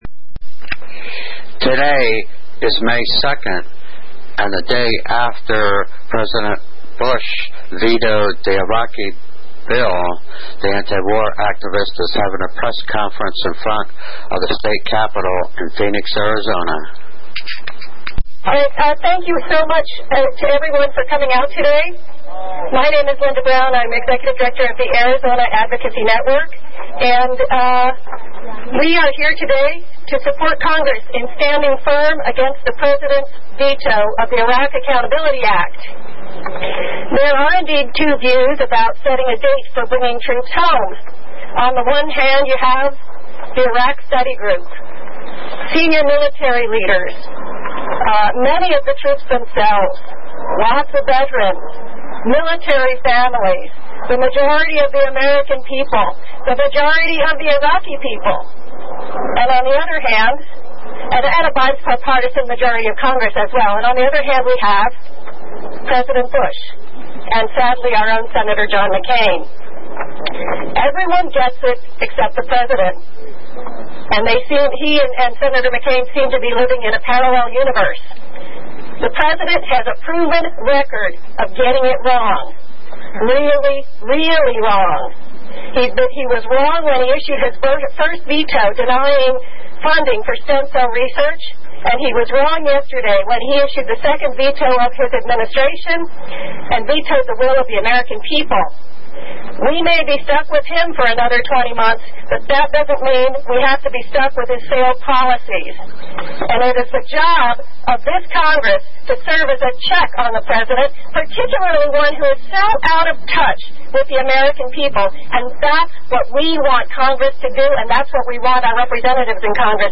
17:25 minute Press Conference in front of State Capitol Phoenix Arizona with Activists and State Legislators
endthewarpressconferencestatecapitolphxaz5-2-07.mp3